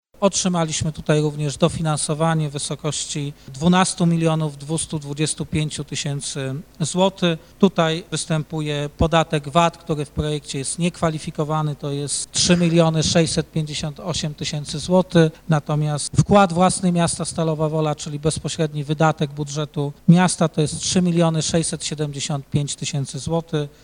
Poinformował o tym na Sesji Rady Miejskiej prezydent miasta Lucjusz Nadbereżny.